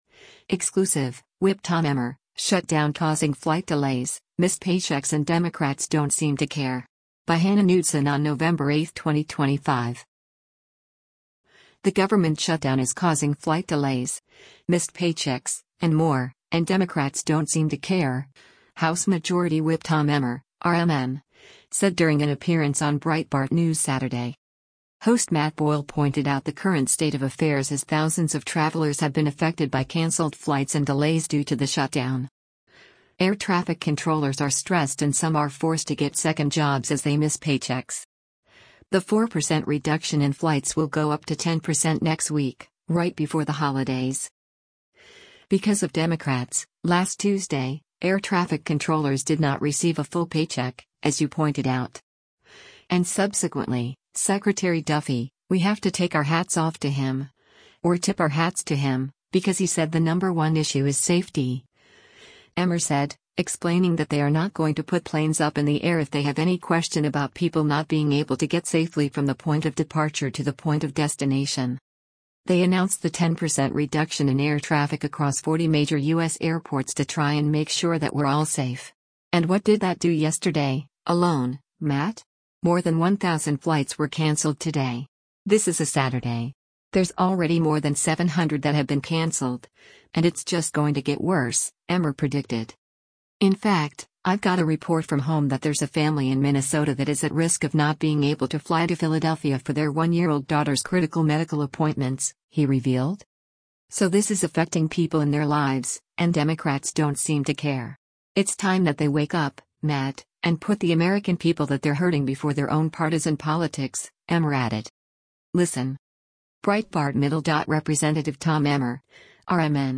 The government shutdown is causing flight delays, missed paychecks, and more, and Democrats “don’t seem to care,” House Majority Whip Tom Emmer (R-MN) said during an appearance on Breitbart News Saturday.